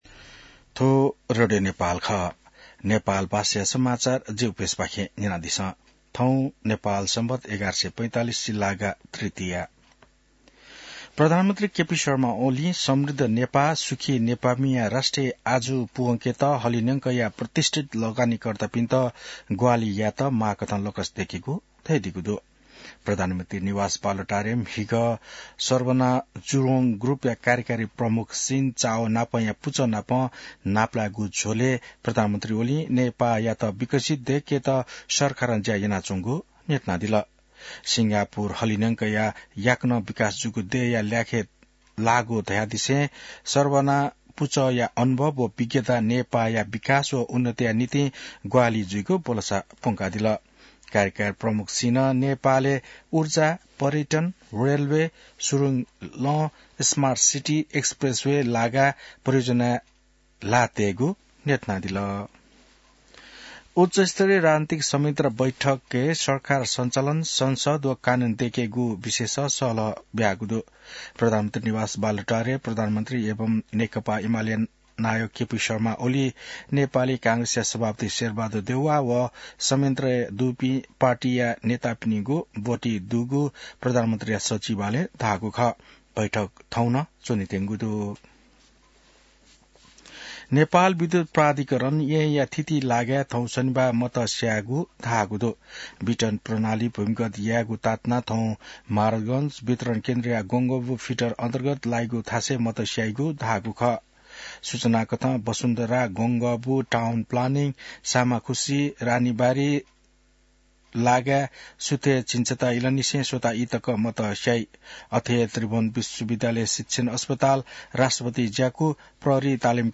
नेपाल भाषामा समाचार : ४ फागुन , २०८१